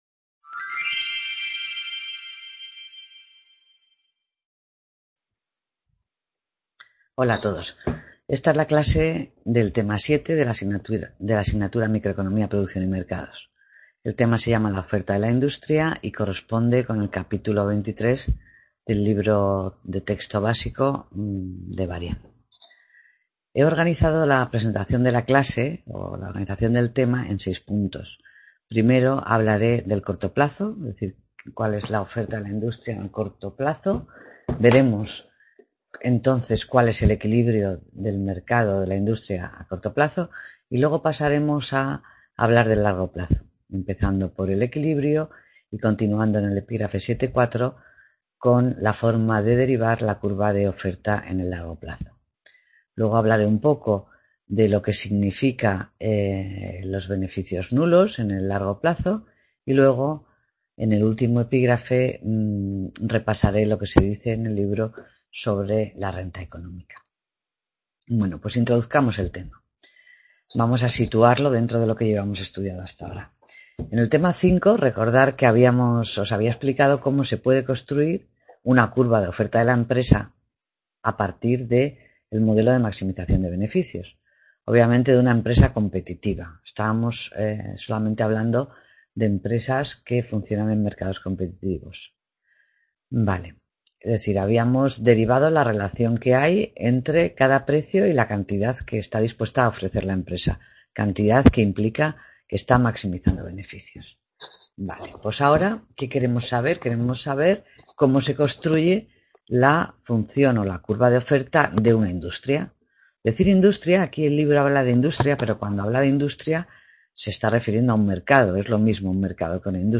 Clase Tema 7: La oferta de la industria | Repositorio Digital